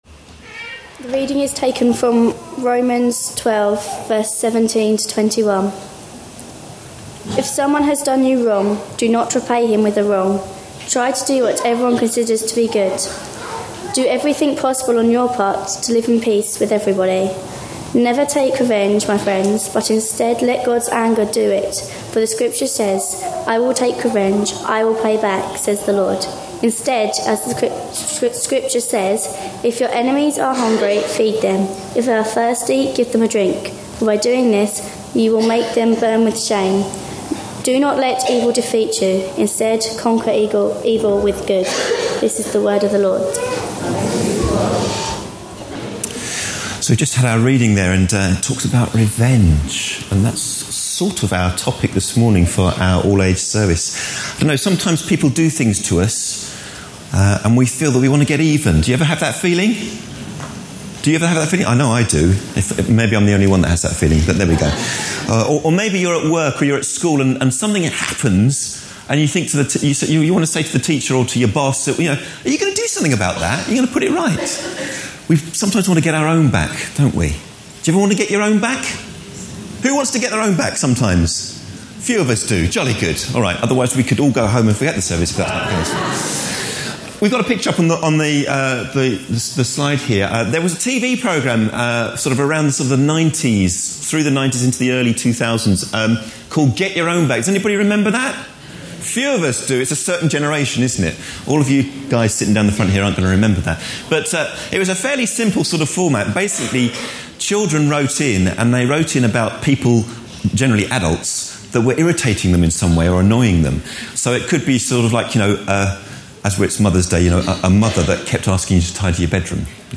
A sermon preached on 18th March, 2012, as part of our Looking For Love (10am Series) series.
(The service took place on Mothering Sunday.)